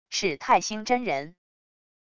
是泰兴真人wav音频